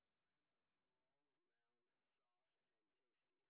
sp21_street_snr0.wav